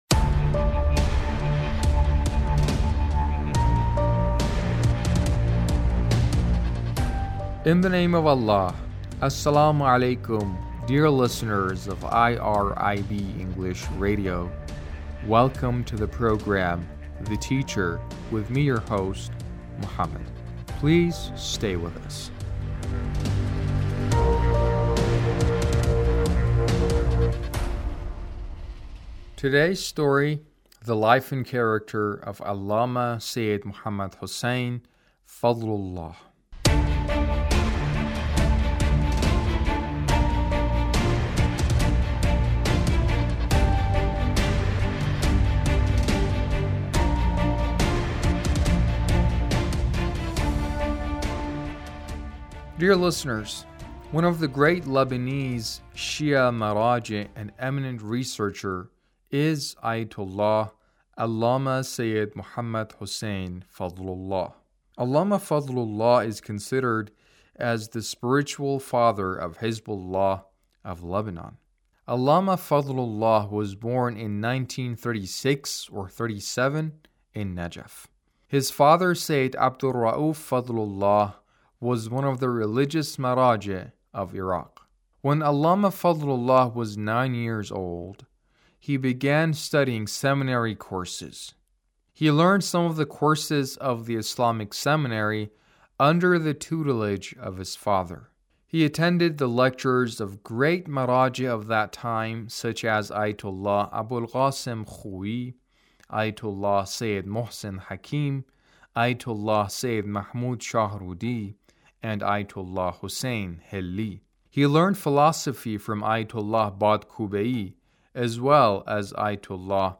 A radio documentary on the life of Allama Muhammad Hussein Fadlullah - 1